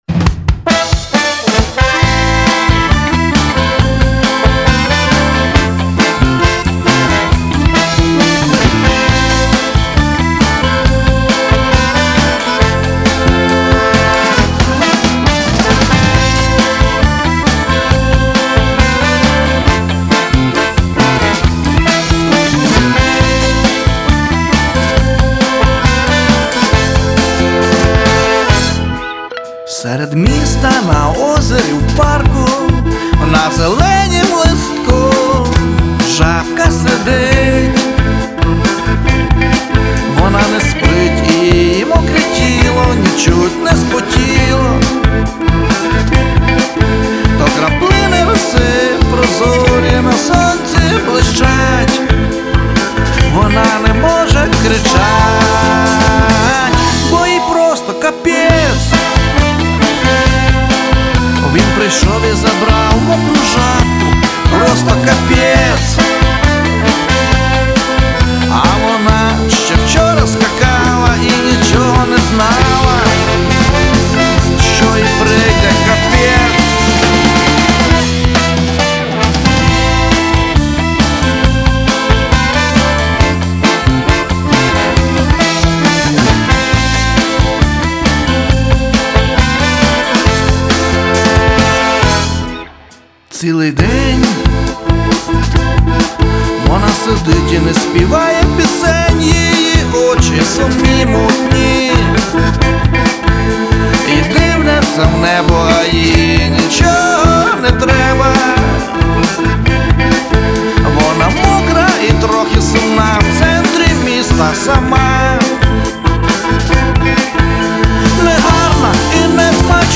Pop [52]